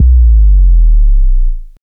puredata/resources/808_drum_kit/classic 808/Fly-08.wav at 2f62dcfa9559c2c932b49ec97680dbd6f42c9a5b